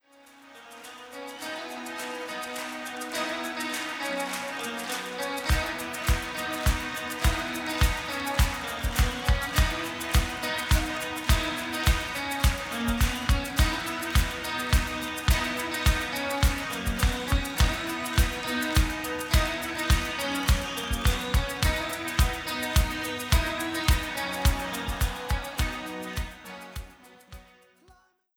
Compare Sample from original CD to newly Digital Refresh.